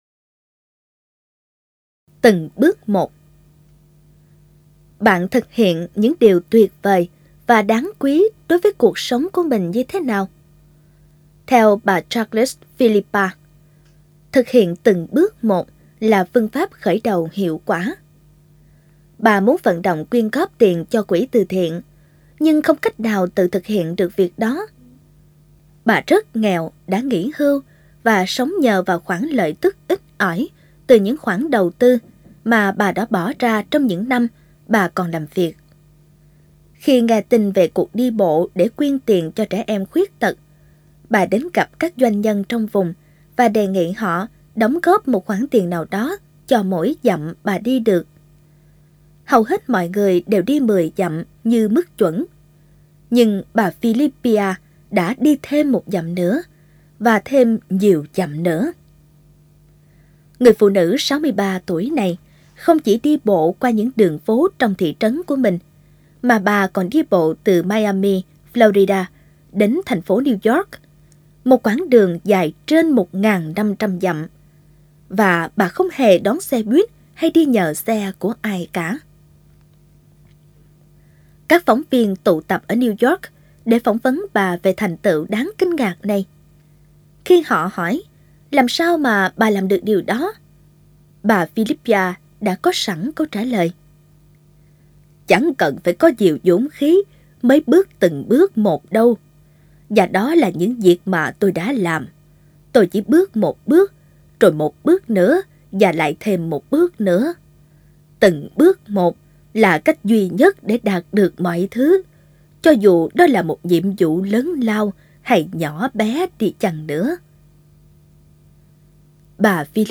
Sách nói | Từng bước một